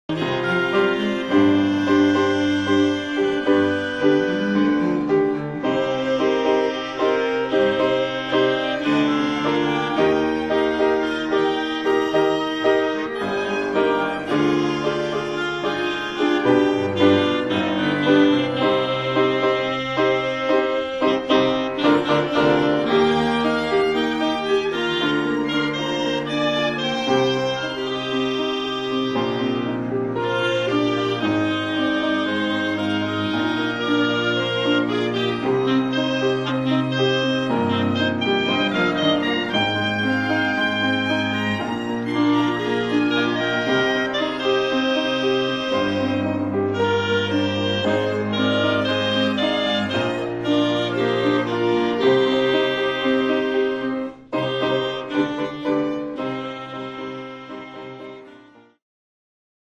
音楽ファイルは WMA 32 Kbps モノラルです。
Piccolo、Oboe、Clarinet、Violin、Cello、Piano